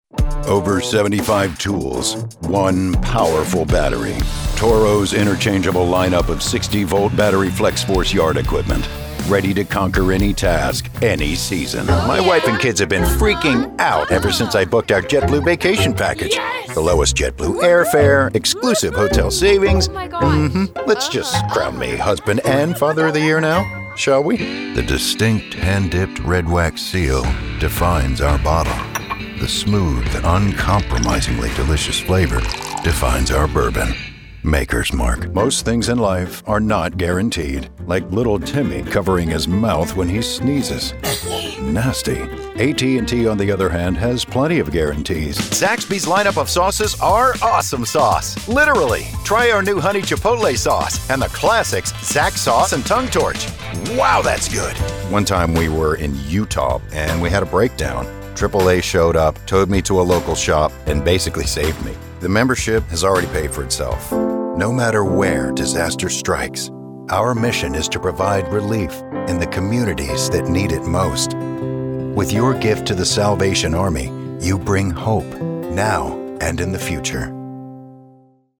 DEMOS
Commercial 1